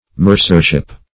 Mercership \Mer"cer*ship\, n. The business of a mercer.